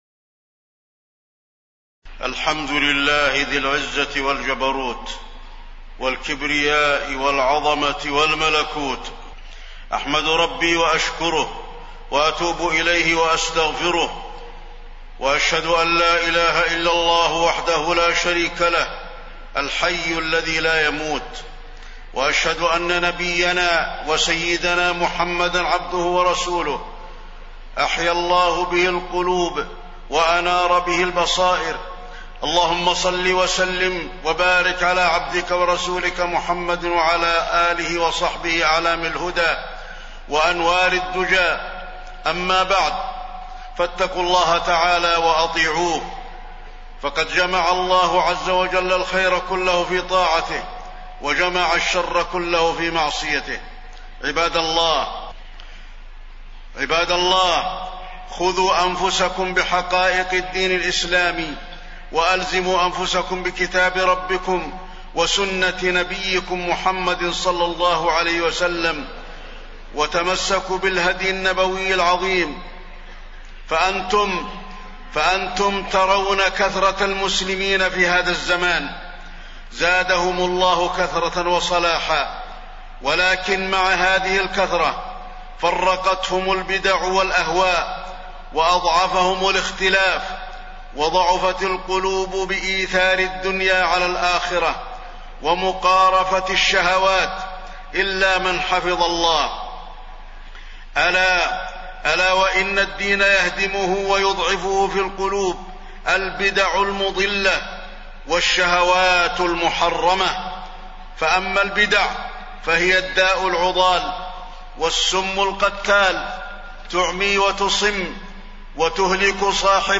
تاريخ النشر ٢٦ ربيع الأول ١٤٣١ هـ المكان: المسجد النبوي الشيخ: فضيلة الشيخ د. علي بن عبدالرحمن الحذيفي فضيلة الشيخ د. علي بن عبدالرحمن الحذيفي البدع والابتداع